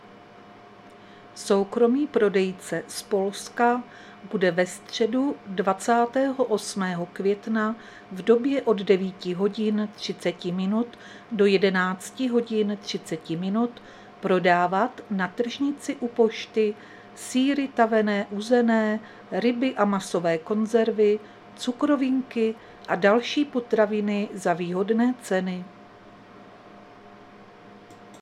Záznam hlášení místního rozhlasu 27.5.2025
Zařazení: Rozhlas